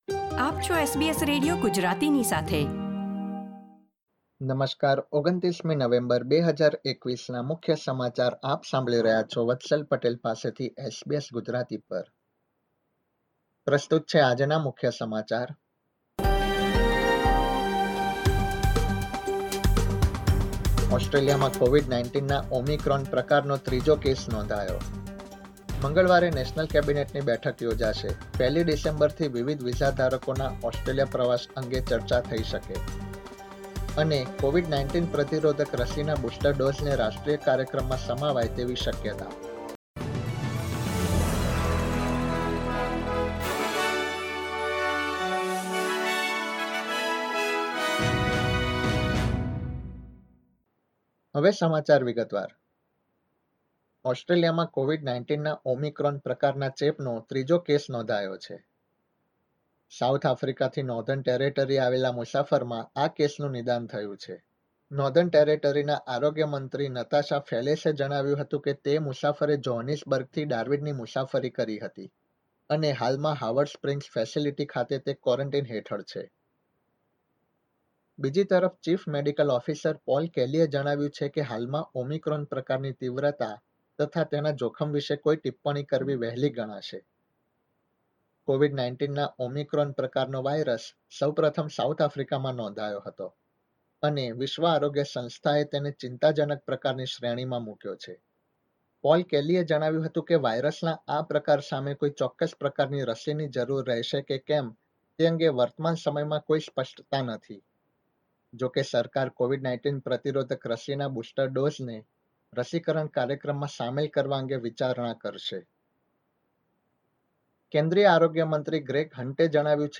SBS Gujarati News Bulletin 29 November 2021